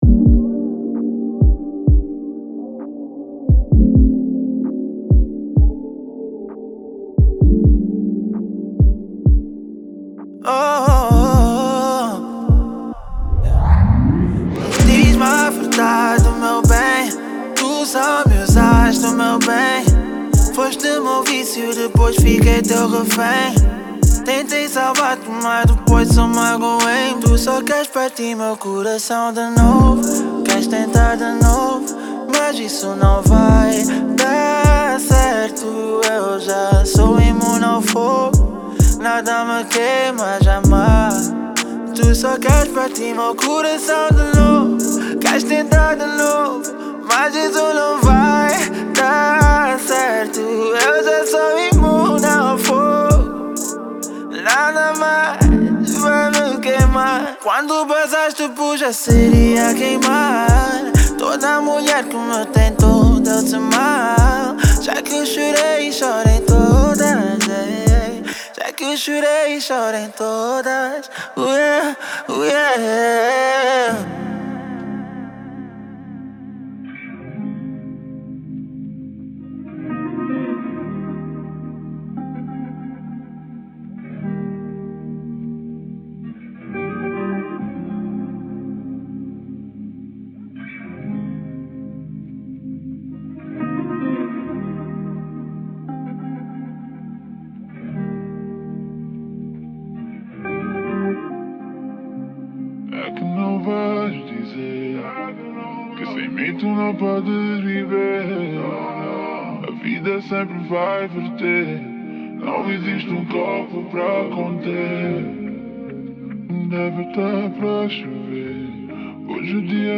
Genero: Pop